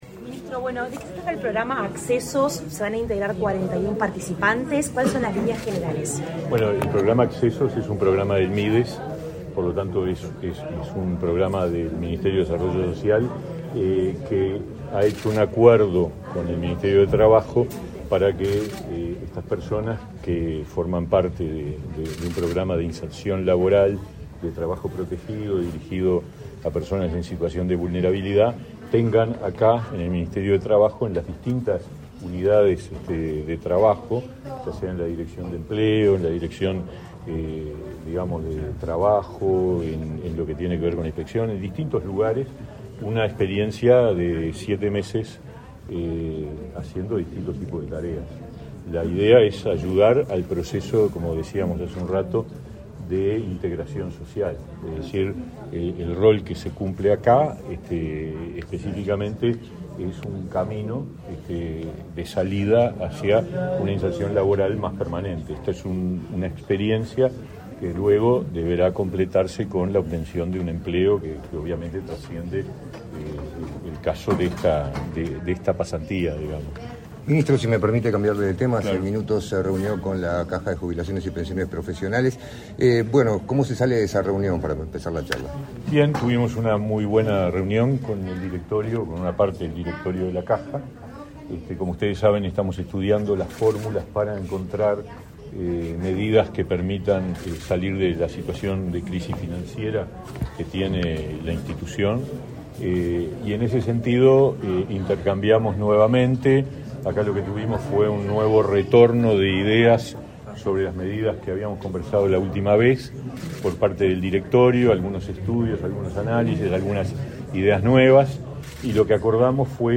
Declaraciones a la prensa del ministro de Trabajo y Seguridad Social, Pablo Mieres
Declaraciones a la prensa del ministro de Trabajo y Seguridad Social, Pablo Mieres 24/05/2022 Compartir Facebook X Copiar enlace WhatsApp LinkedIn Tras participar en la firma de convenio entre los ministerios de Trabajo y Seguridad Social y de Desarrollo Social, este 24 de mayo, Pablo Mieres efectuó declaraciones a la prensa.